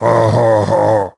frank_vo_02.ogg